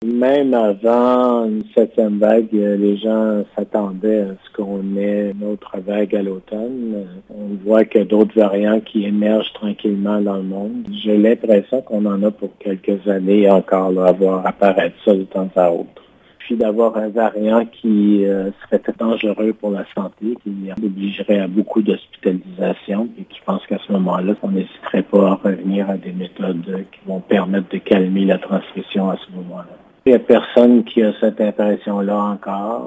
Pour réécouter l’entrevue avec le directeur de la santé publique régionale :